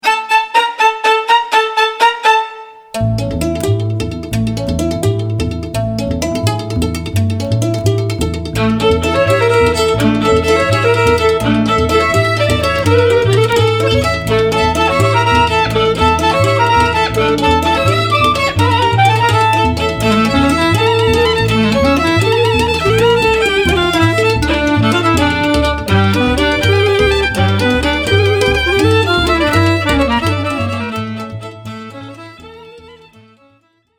Violin
C Clarinet
Accordions, Tsimbl
Bass Cello
Genre: Klezmer.